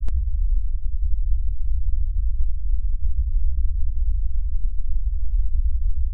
I added the default brown noise.
I used Low Pass Filter set at 70Hz, and used roll-off 48 dB.
It works and I get a nice low frequency noise but I get this terrible ringing noise.
There is no high pitched ringing noise there, just a click at the beginning and low frequency noise.